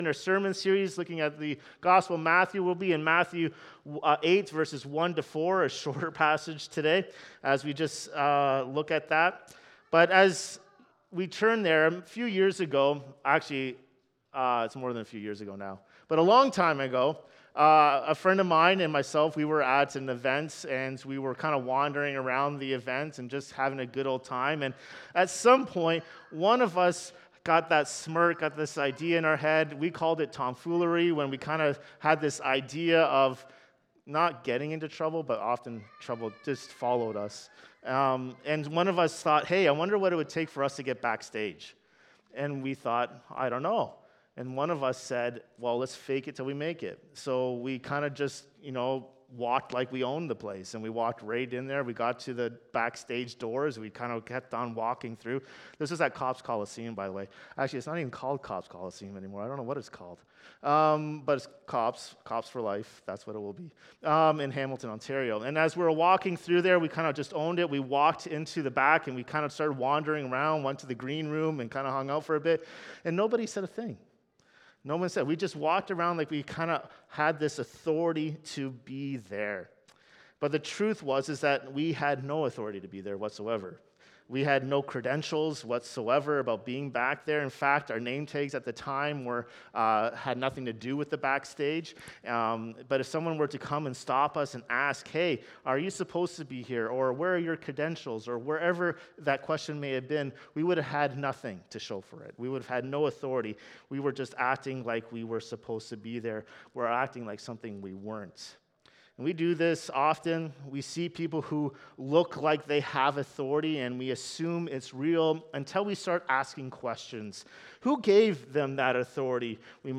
This sermon calls us to consider how we respond to Jesus’ authority.